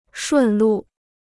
顺路 (shùn lù) Dicionário de Chinês gratuito